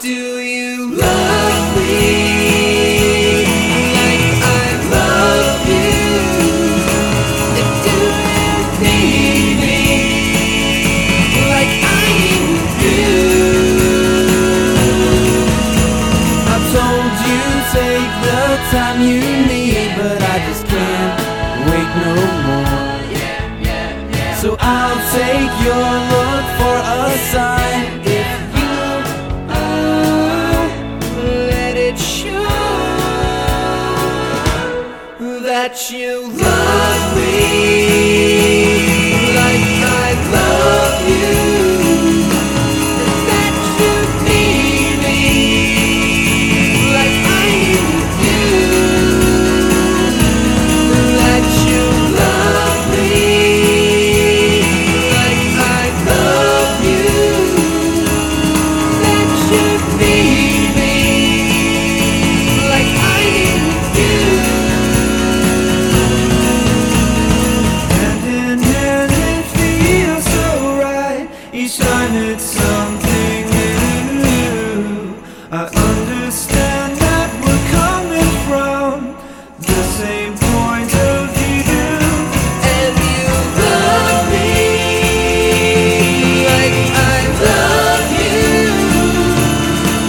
ROCK / SOFTROCK. / CHORUS
ソフトロック好き昇天の美麗な男女ハーモニー